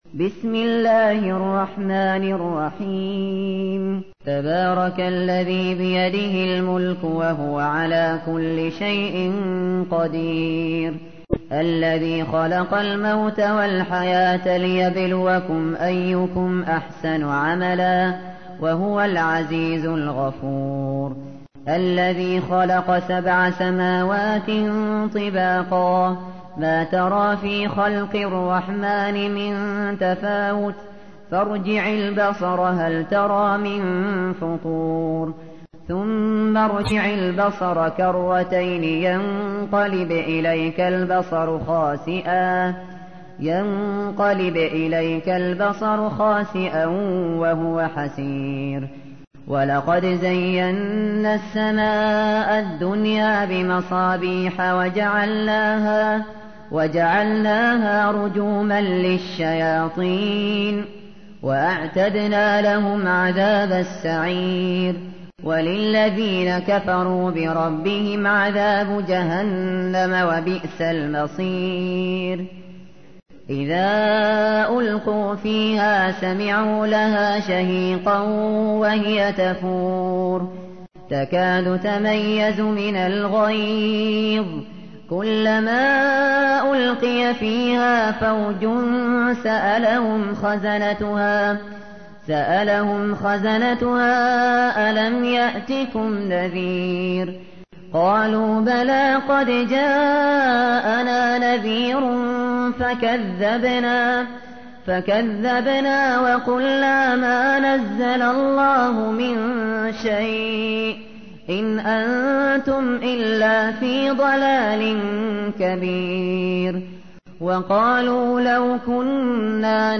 تحميل : 67. سورة الملك / القارئ الشاطري / القرآن الكريم / موقع يا حسين